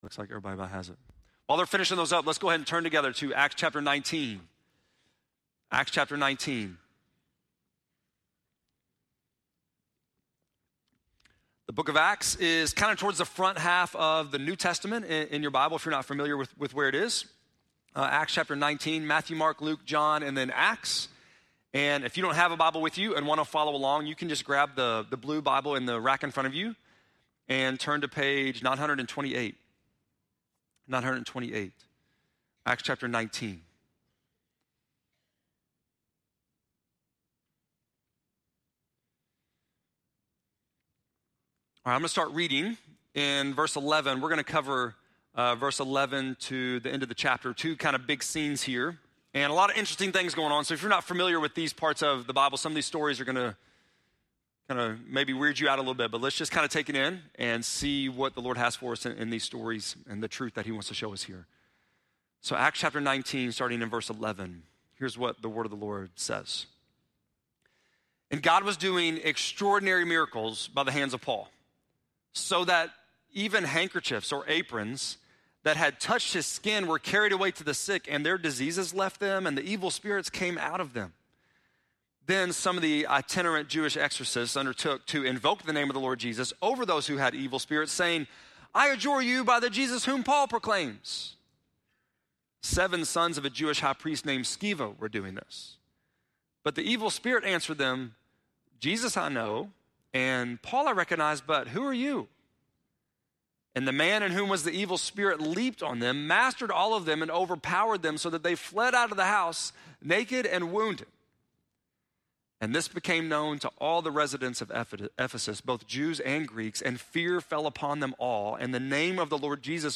10.13-sermon.mp3